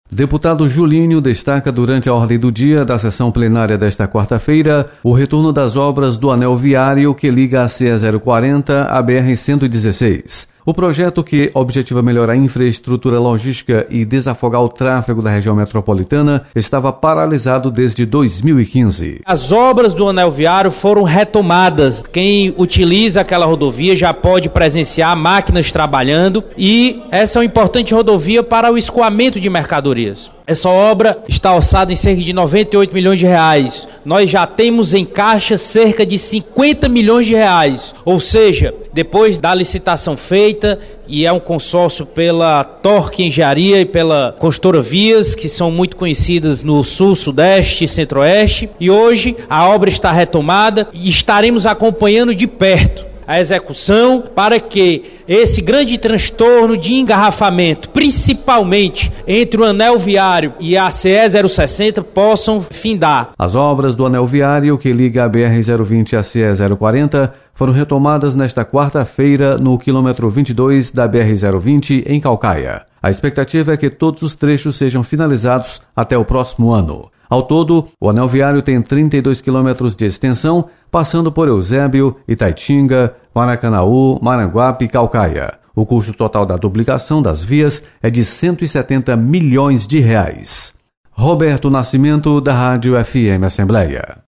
Deputado Julinho anuncia retomada das obras do anel viário de Fortaleza. Repórter